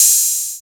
12 OP HAT.wav